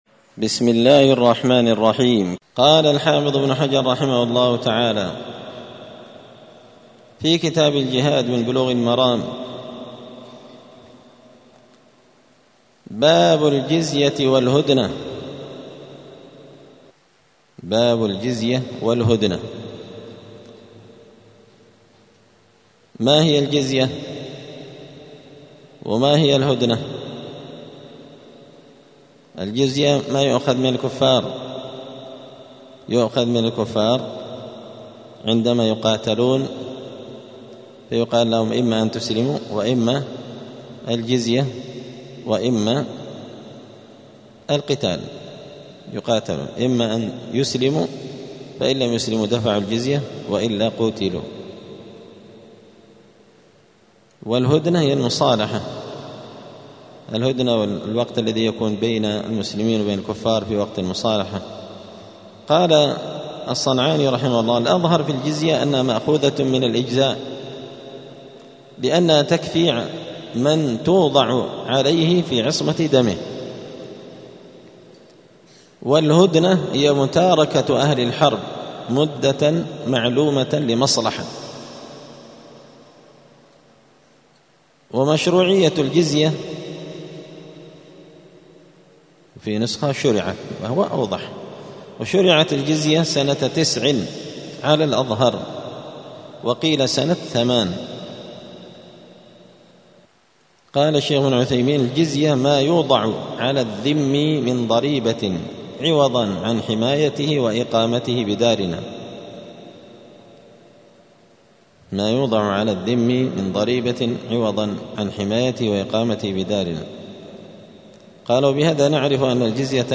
*الدرس الخامس والعشرون (25) {باب الجزية والهدنة}*
دار الحديث السلفية بمسجد الفرقان قشن المهرة اليمن